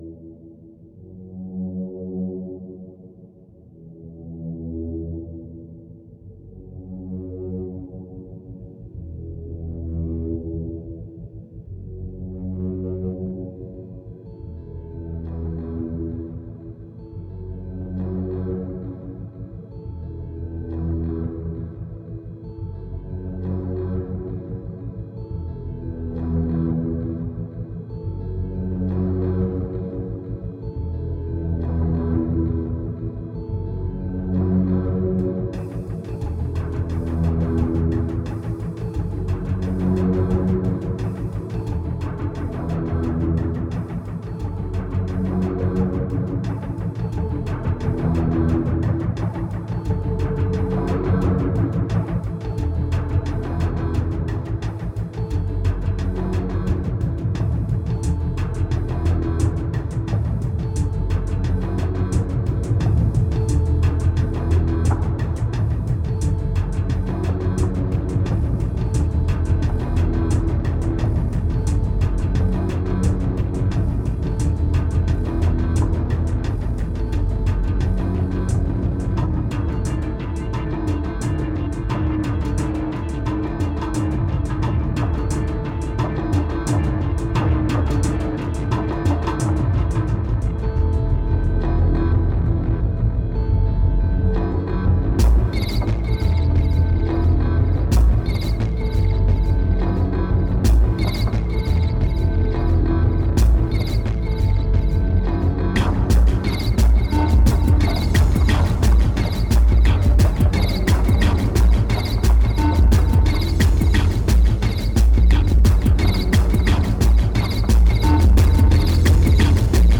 2070📈 - 5%🤔 - 88BPM🔊 - 2011-04-09📅 - -146🌟